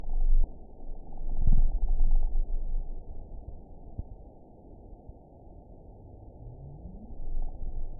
event 916946 date 02/21/23 time 09:57:59 GMT (2 years, 2 months ago) score 8.57 location TSS-AB05 detected by nrw target species NRW annotations +NRW Spectrogram: Frequency (kHz) vs. Time (s) audio not available .wav